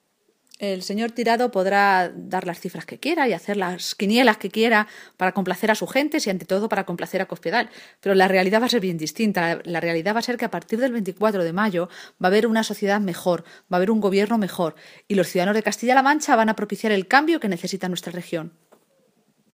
La portavoz socialista contesta a Tirado que sus “cábalas” sobre los resultados electorales del 24-M están muy lejos de la realidad.
Cortes de audio de la rueda de prensa